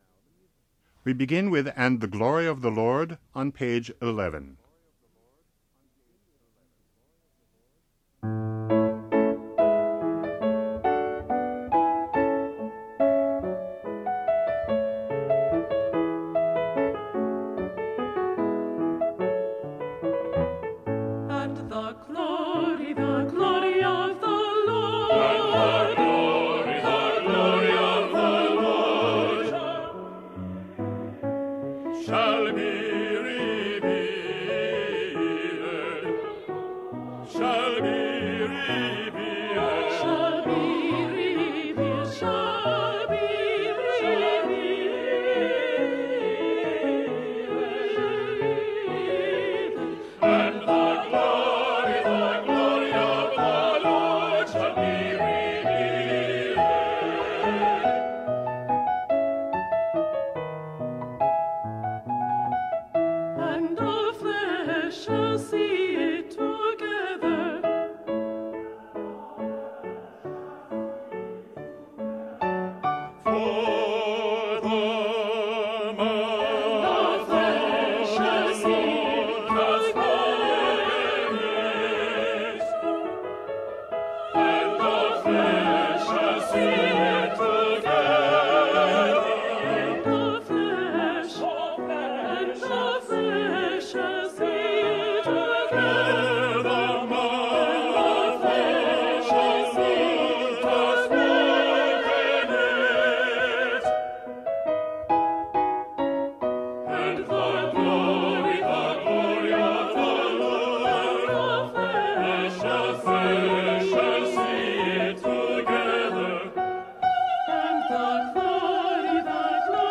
They are divided into two sections with either Alto and Bass together  or  Soprano and Tenor – since in each case the parts are well separated and tonally different you should be able to hear your part fairly clearly.
Alto/Bass